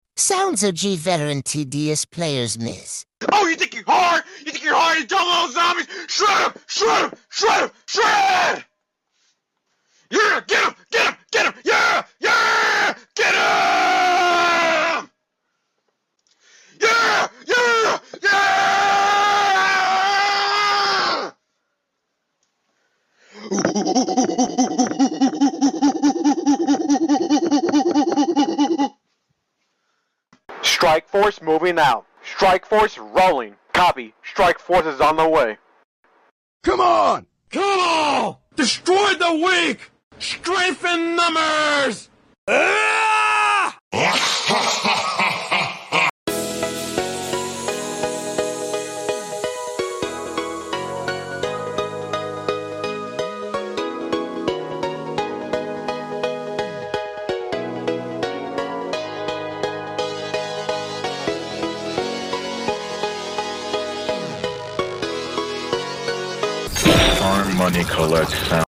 OG TDS sounds every TDS sound effects free download